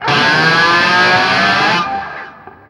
DIVEBOMB 6-R.wav